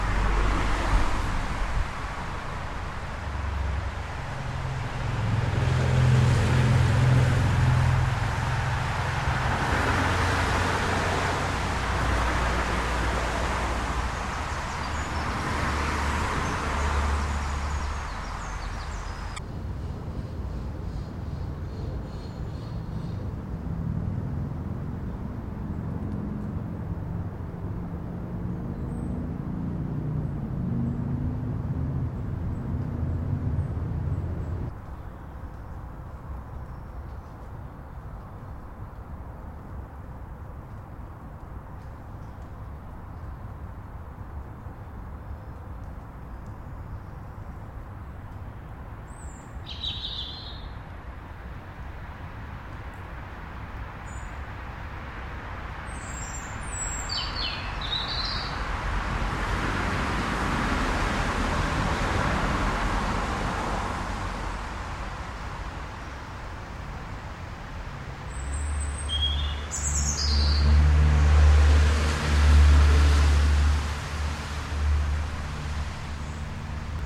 大气 " EXT 郊区路4号附近
描述：不是很持续的交通。立体声录音，面向道路。
Tag: 道路 交通 汽车